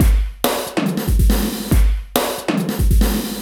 E Kit 32.wav